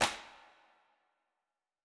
TC2 Clap7.wav